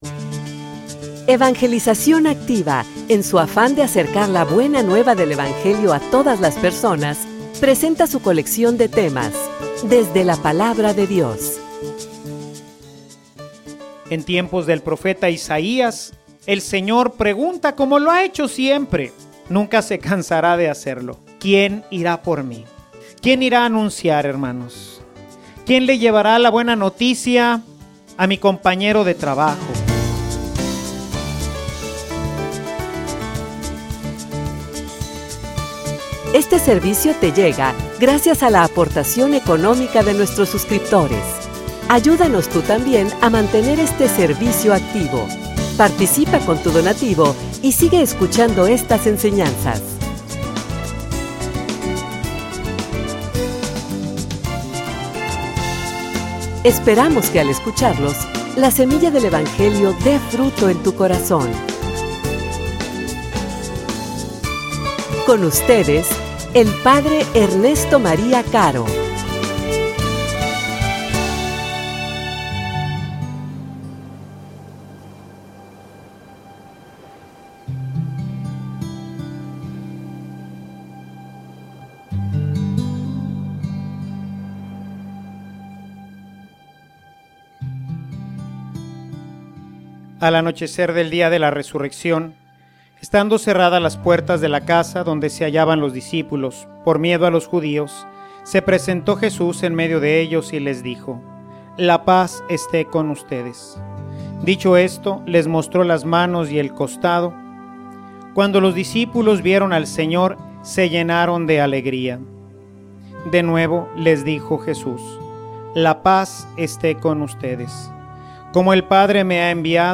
homilia_Estoy_ansioso_por_predicar.mp3